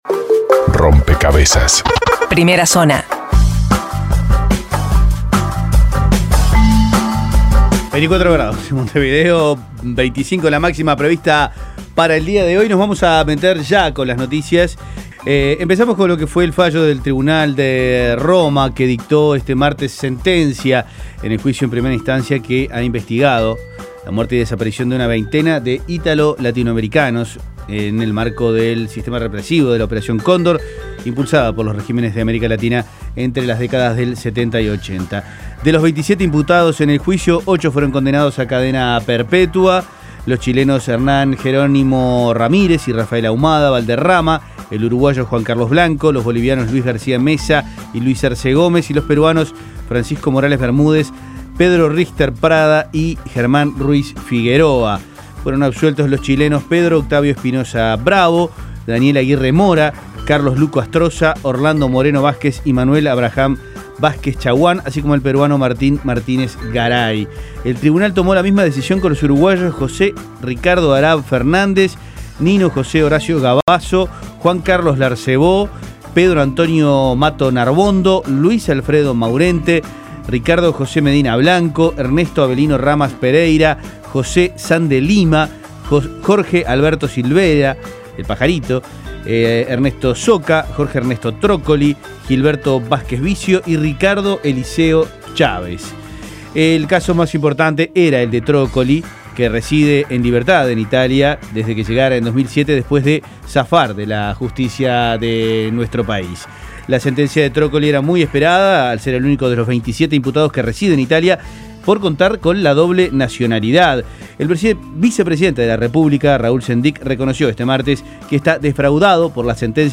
Las principales noticias del día, resumidas en la Primera Zona de Rompkbzas.